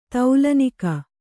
♪ taulanika